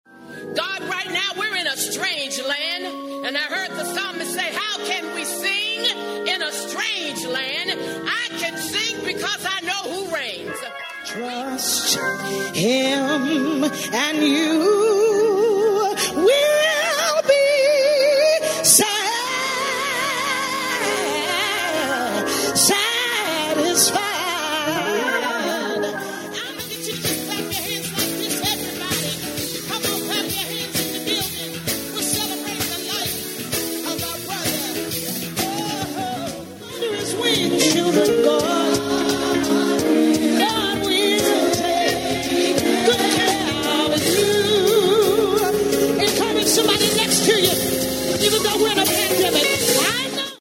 When they reopen, though, I feel like some of the gospel music they played at Floyd’s funeral would really perk up the mood at the county DMV offices.
Tell me you wouldn’t show up early to get your registration renewed if you knew you could expect a choir with robes and what not yellin’ at you [testifyin’] to, I swear I’m not making this up, comfort someone next to you, even though we’re in a pandemic.